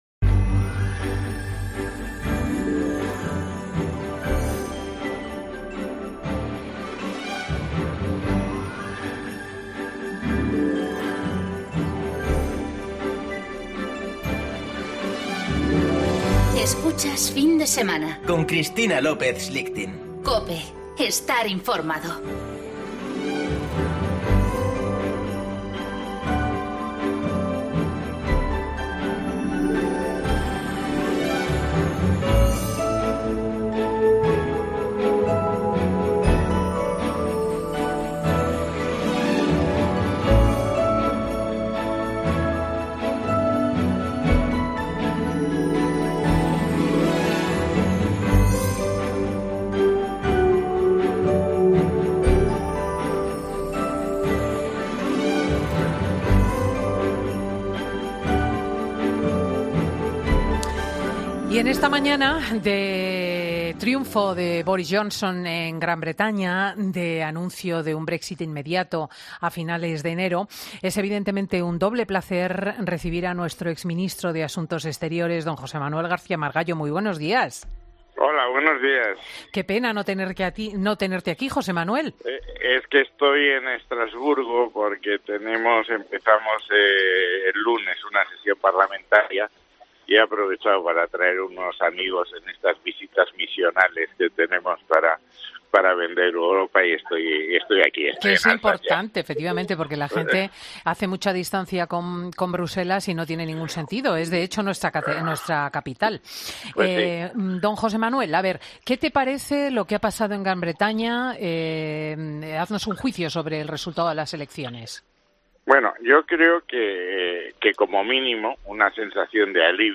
El exministro de Exteriores habla con Cristina López Schlichting sobre las elecciones en Reino Unido y las negociaciones PSOE-ERC